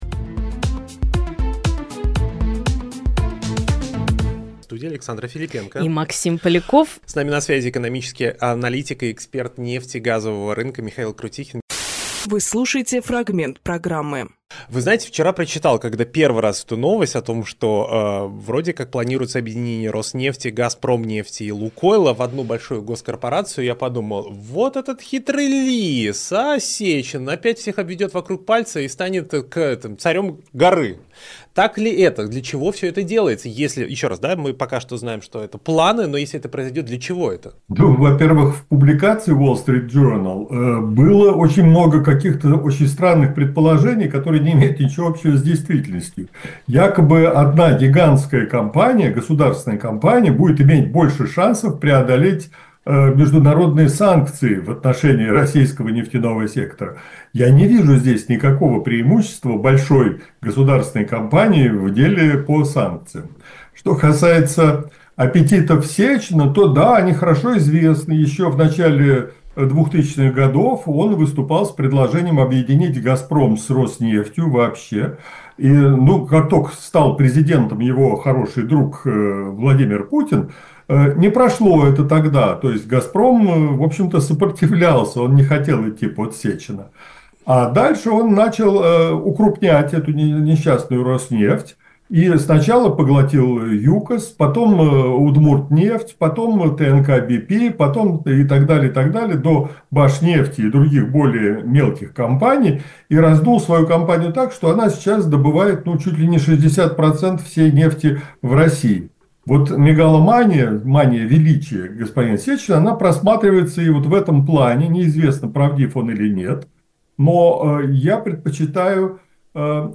Фрагмент эфира от 10.11.24